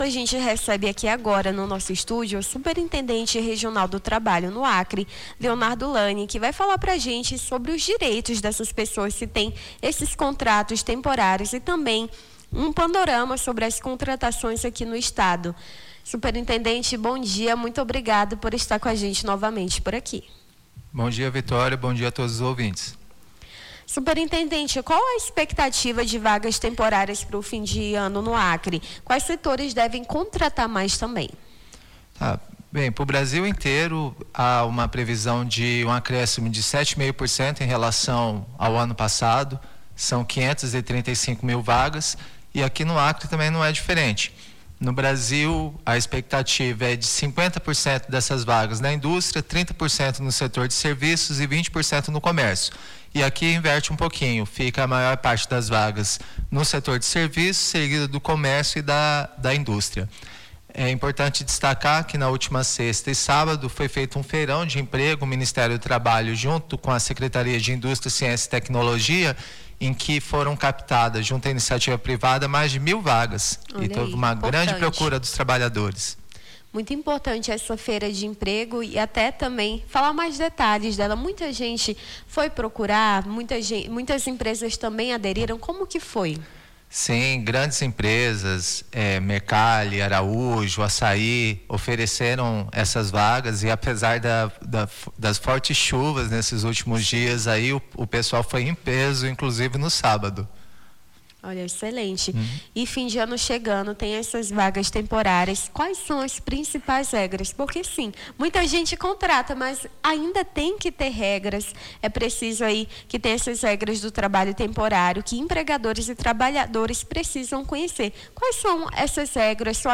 Nesta segunda-feira, 08, recebemos no stúdio da rádio CBN Amazônia o Superintendente do Trabalho, Leonardo Lani, que falou sobre contratações e direitos trabalhistas para quem consegue vagas temporárias no final de ano.